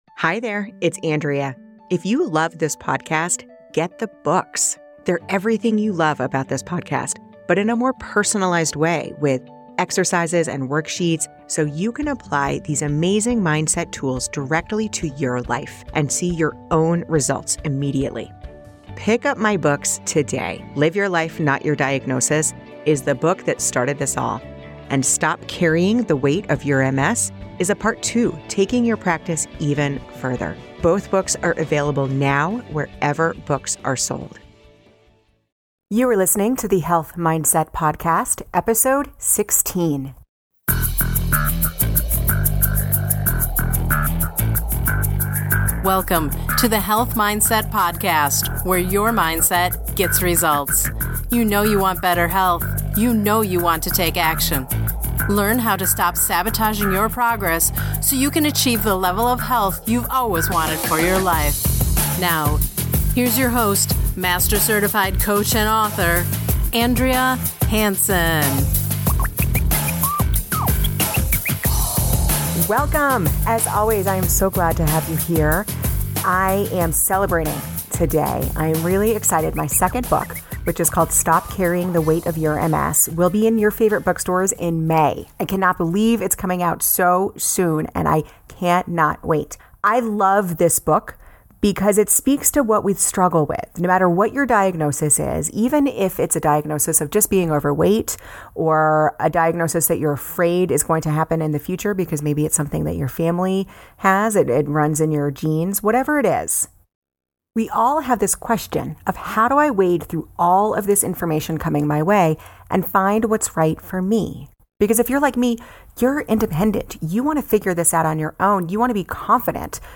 This is the first in a three-part series of podcasts where I talk about the first three chapters in my new book, Stop Carrying the Weight of Your MS. In this week's episode, I talk about the behind the scenes of Chapter 1 and then read the entire chapter.